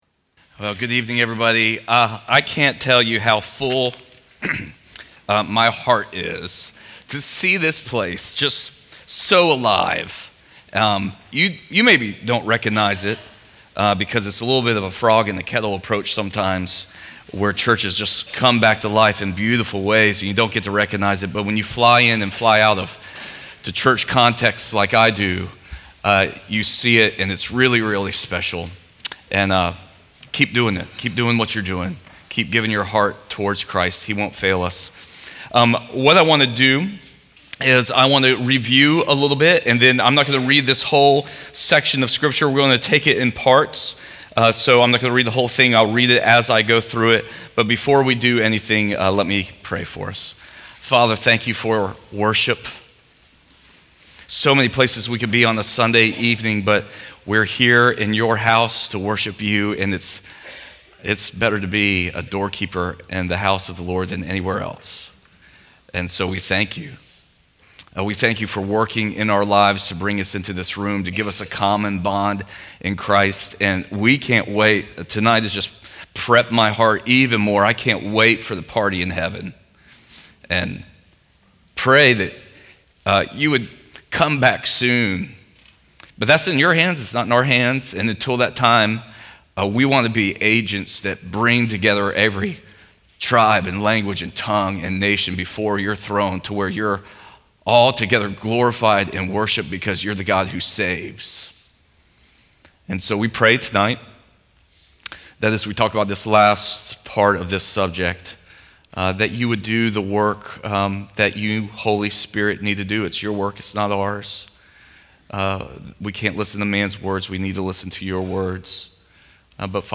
Message: "Banquet Message" from Guest Speaker - First Presbyterian Church of Augusta